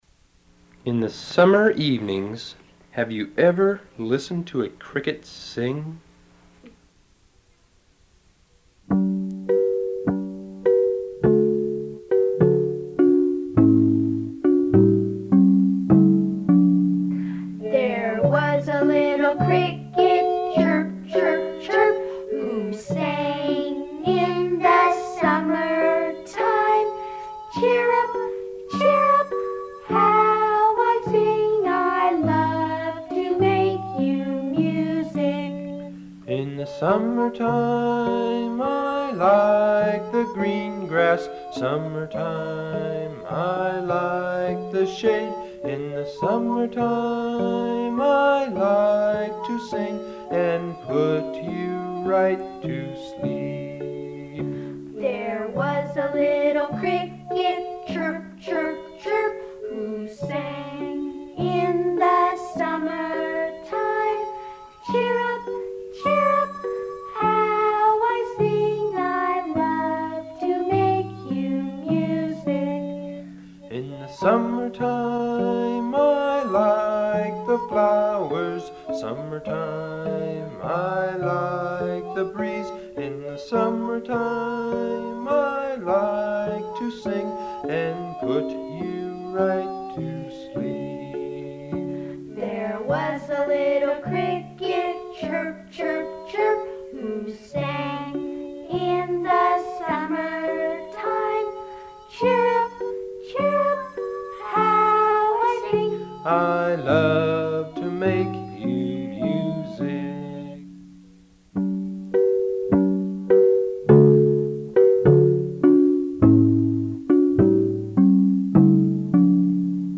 These are children songs for fun and learning.